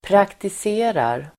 Uttal: [praktis'e:rar]